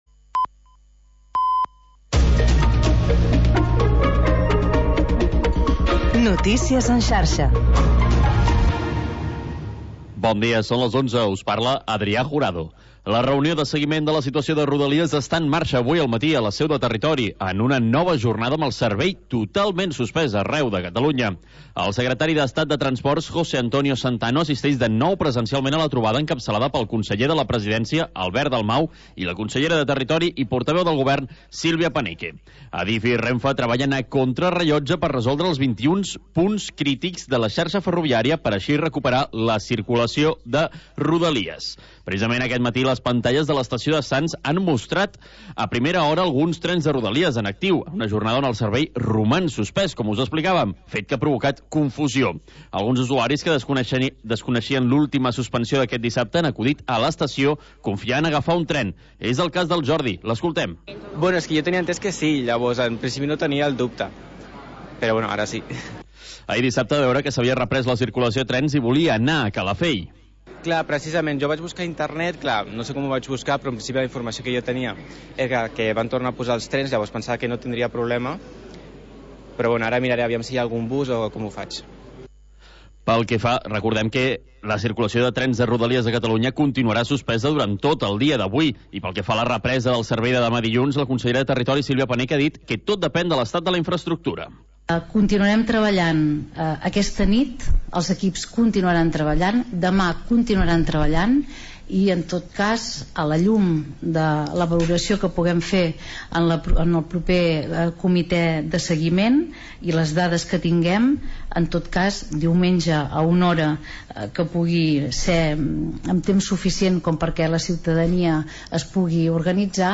Programa variat amb format de magazín amb seccions relacionades amb l'havanera i el cant de taverna.